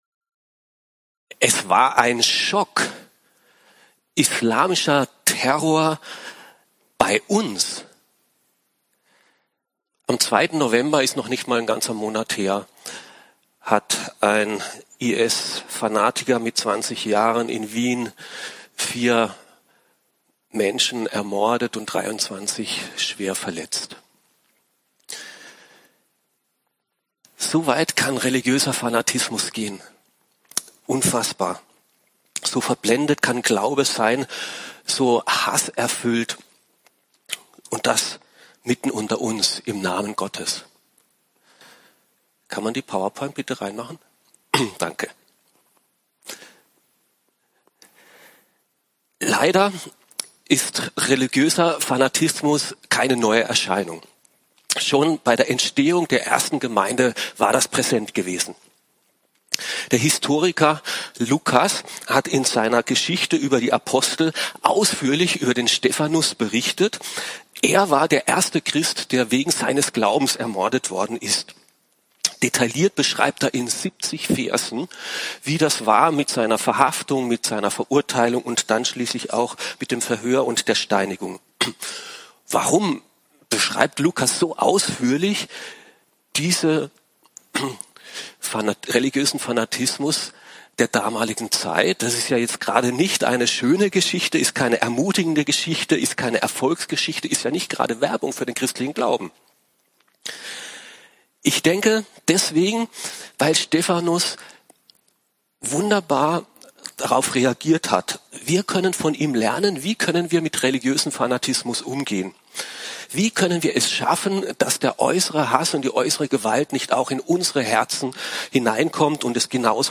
Eine predigt aus der serie "Apostelgeschichte."